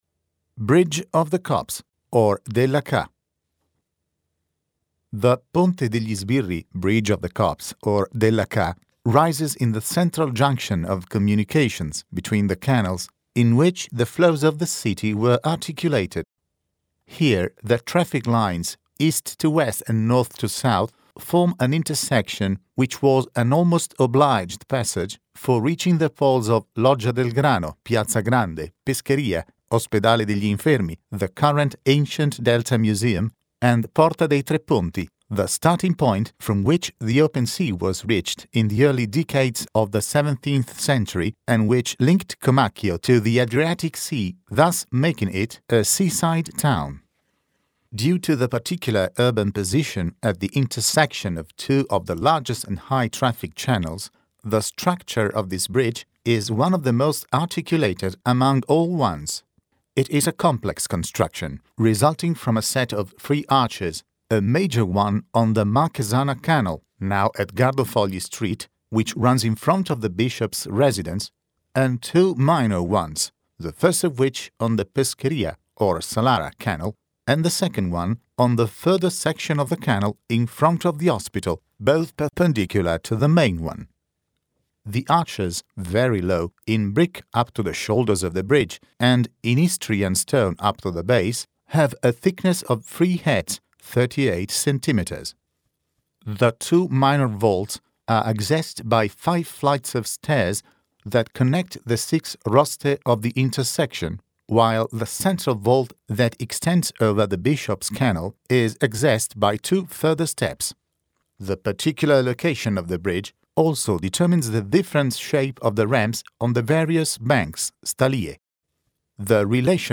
AUDIOGUIDA DEL LUOGO IN LINGUA ITALIANA